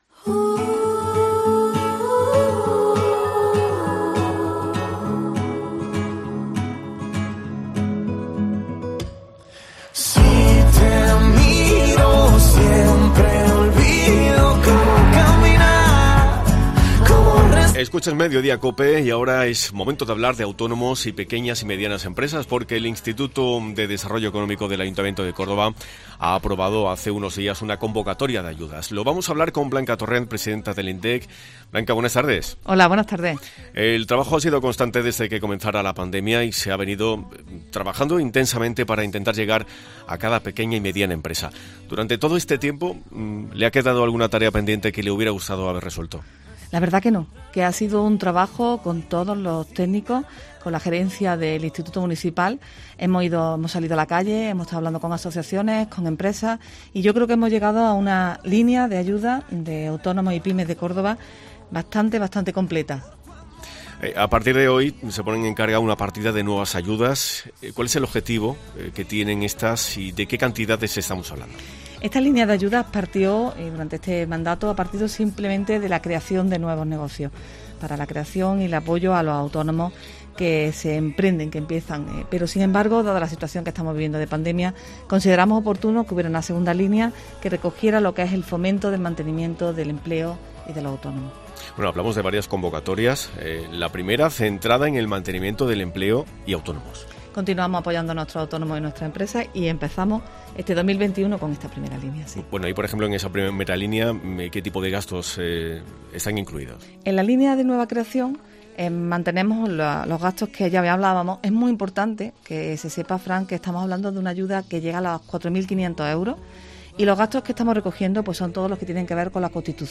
Esta primera convocatoria de ayudas de 2021 tiene como particularidad la inclusión de una línea específica para gastos corrientes “que ayudará a nuestras empresas a afrontar su día a día y a mejorar la salud de sus actividades y empleos; y en la que tendrán especial atención los negocios ubicados en nuestro Centro Histórico, en la Judería, especialmente azotada por la crisis”, ha explicado la presidenta del IMDEEC y delegada de Reactivación Económica, Blanca Torrent, en los micrófonos de COPE.